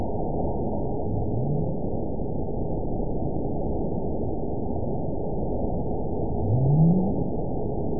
event 920791 date 04/09/24 time 05:14:50 GMT (1 year, 2 months ago) score 9.56 location TSS-AB01 detected by nrw target species NRW annotations +NRW Spectrogram: Frequency (kHz) vs. Time (s) audio not available .wav